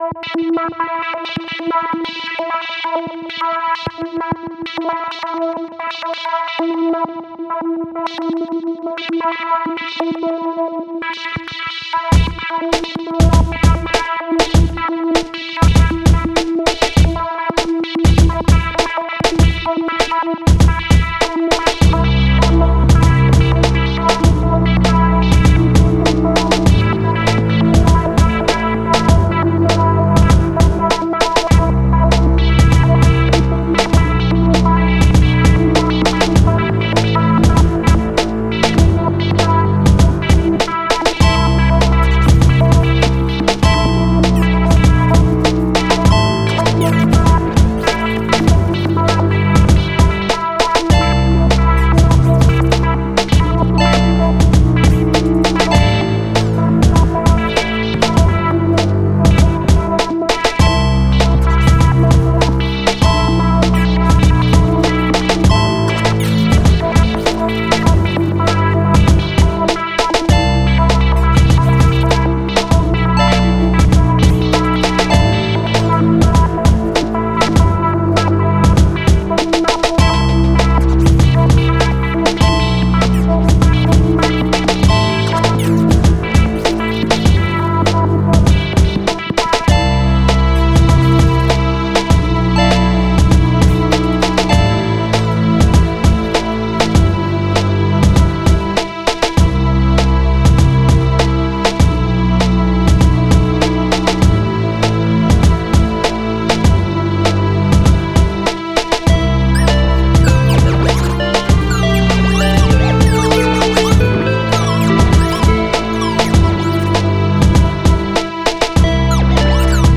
the last instrumental piece